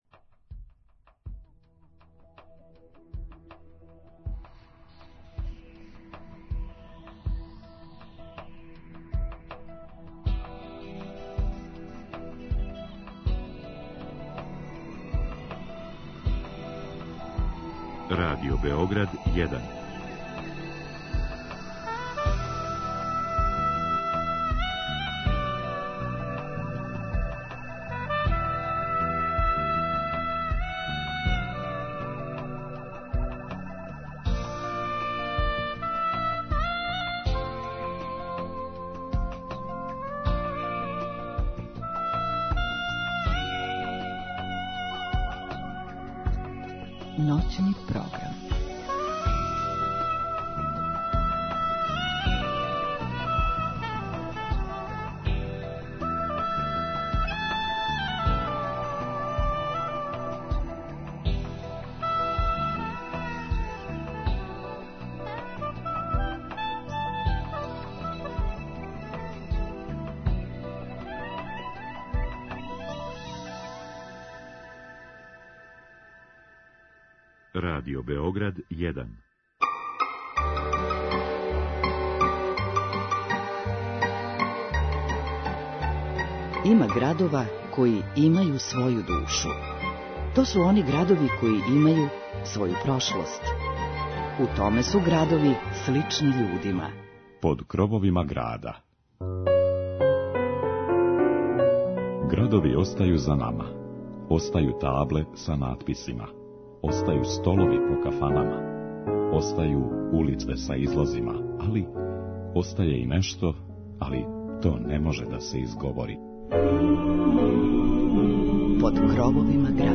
Овога пута слушаоци ће имати прилику да чују интересантне приче из источне Србије, обојене музиком тог краја.